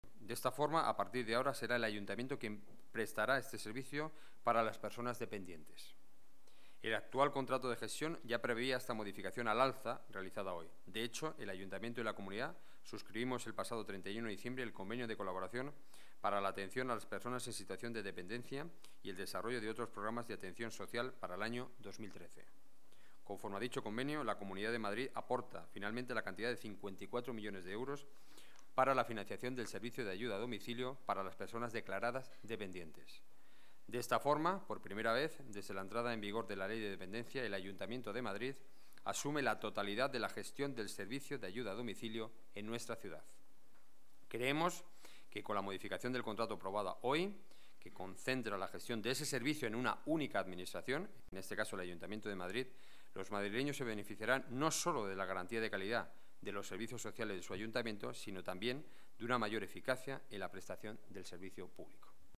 Nueva ventana:Declaraciones de Enrique Núñez sobre el Servicio de Ayuda a Domicilio